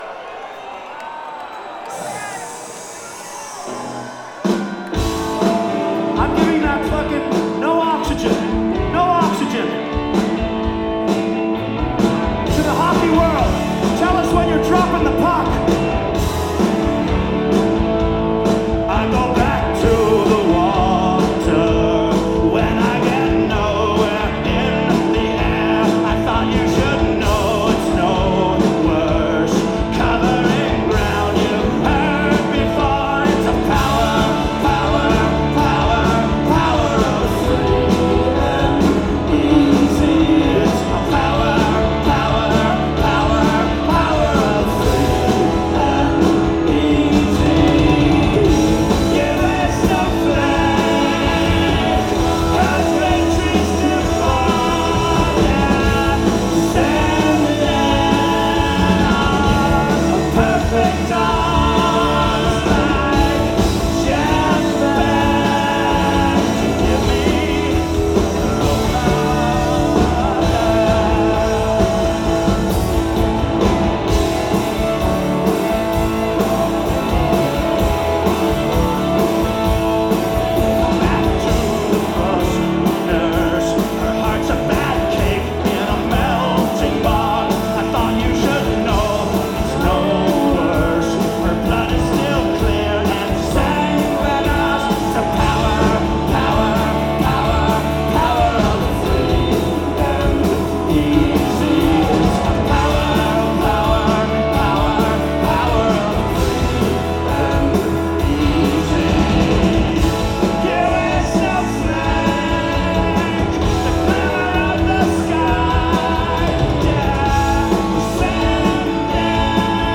(5th Live Performance)